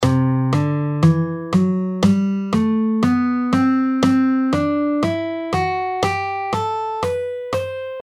C Lydian
C Lydian: C – D – E – F♯ – G – A – B – C. A major scale with a raised fourth, creating a dreamy, almost ethereal quality.
C-Lydian-4th-Mode-Of-C-Major.mp3